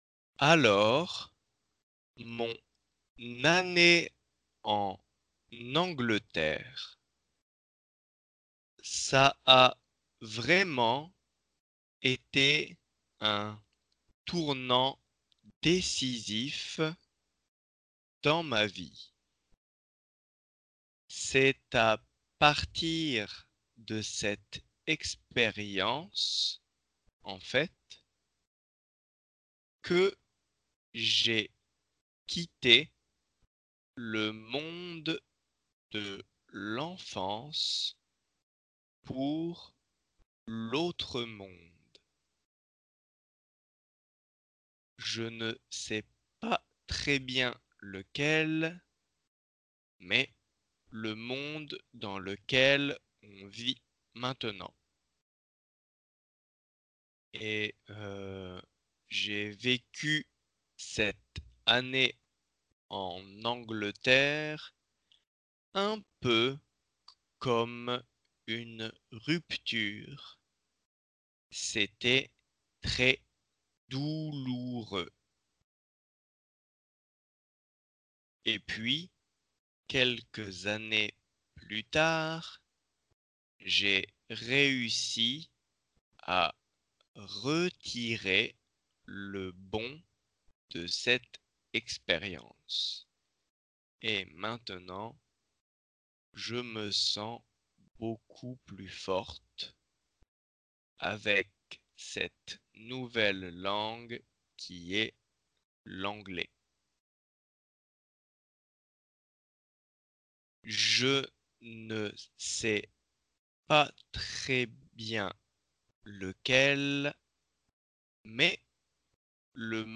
音楽活動に携わっているある人の語りです。
テキスト　下線部は　①　　②　ともに本文音声中で末尾に　répétition　しています。